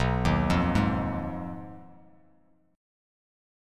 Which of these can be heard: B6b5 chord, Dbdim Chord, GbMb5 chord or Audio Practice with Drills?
B6b5 chord